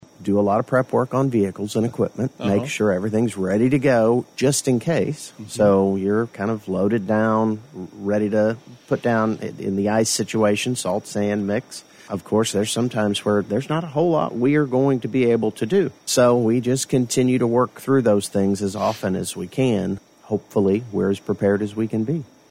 On a recent episode of KWON's COMMUNITY CONNECTION, Washington County Commissioner Mitch Antle said,